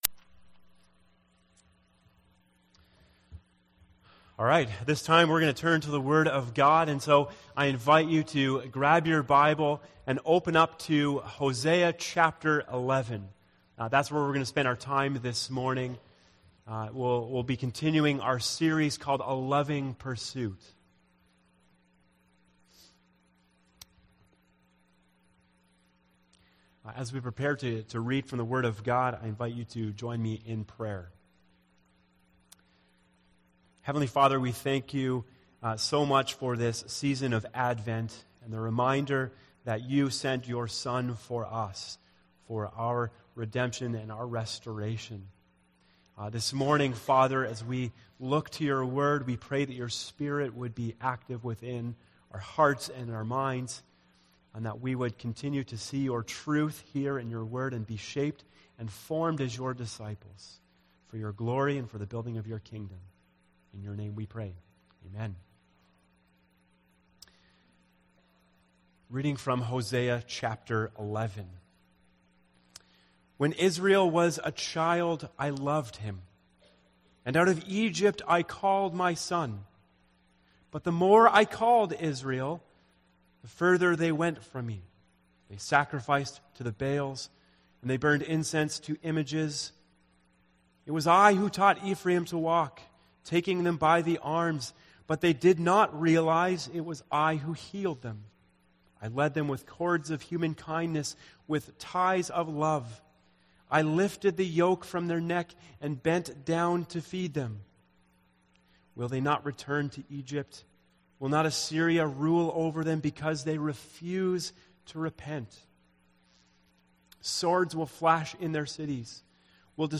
A message from the series "A Loving Pursuit."